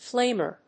/ˈflemɝ(米国英語), ˈfleɪmɜ:(英国英語)/
flamer.mp3